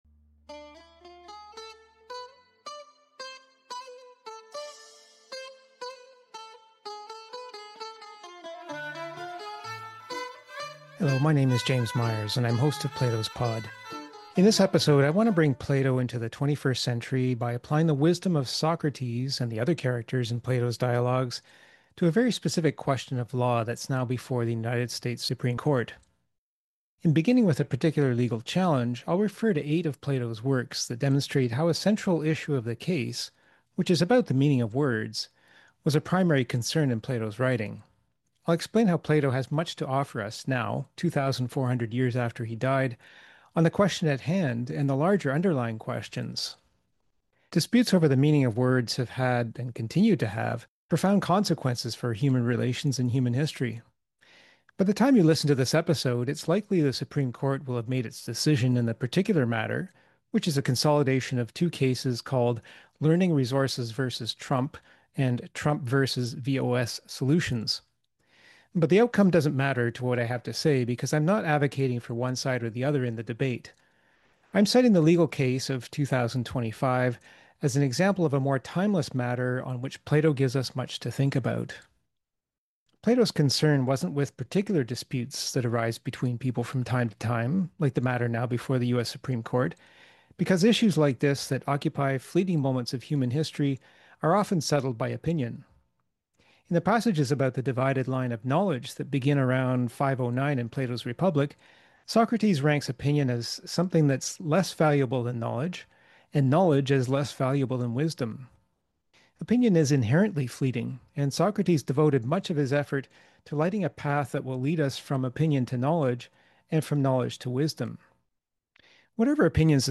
Episodes are lightly edited for clarity, with care to avoid compromising the contributions made by participants.